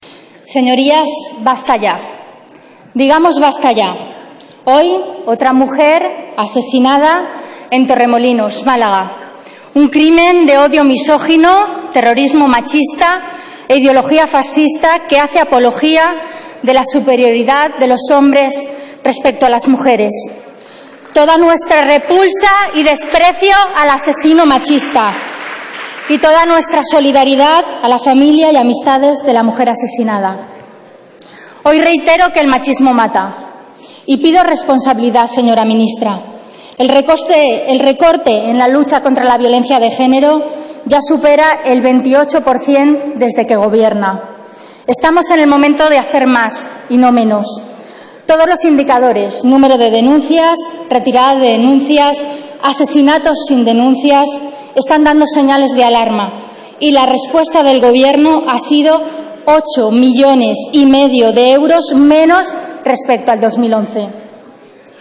Fragmento de la intervención de Carmen Montón en el debate de las enmiendas a los presupuestos del 12/11/2013